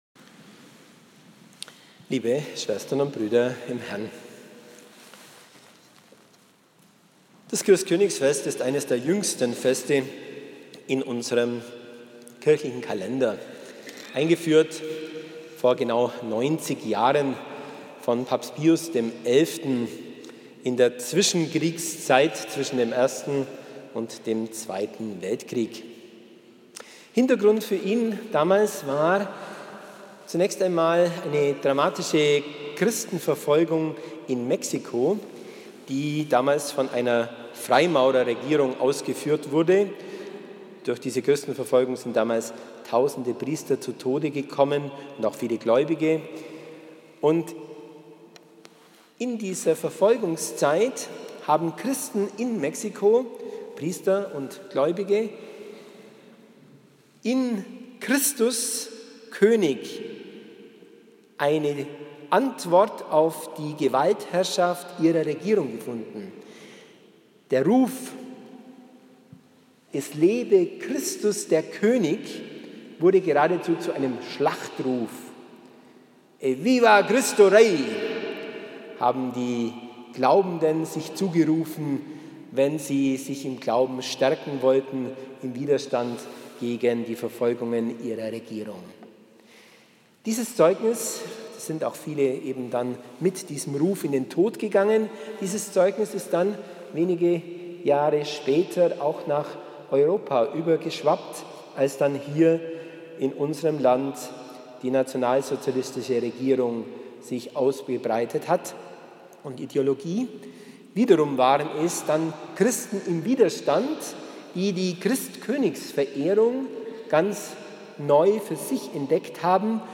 Predigten 2015